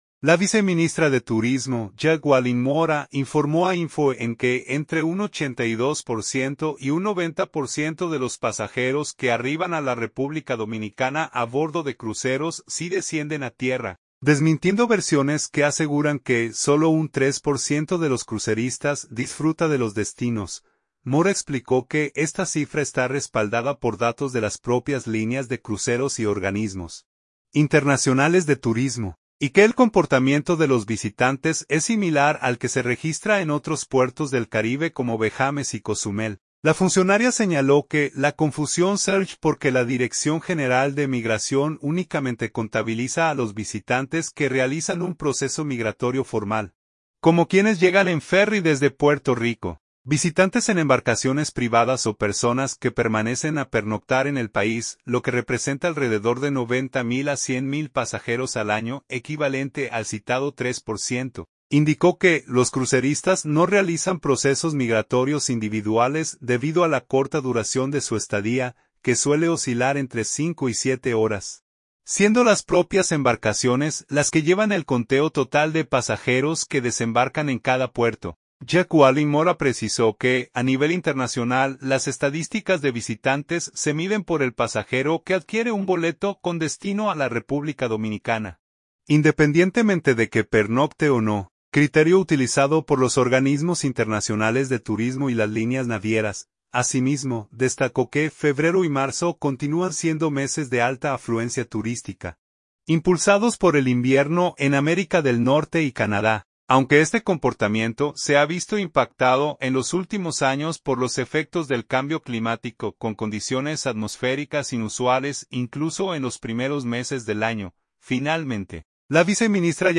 Jacqueline Mora | Viceministra de Turismo